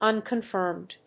un・con・firmed /`ʌnkənfˈɚːmd‐fˈəː‐/
• / `ʌnkənfˈɚːmd(米国英語)
• / ˌʌnkʌˈnfɜ:md(英国英語)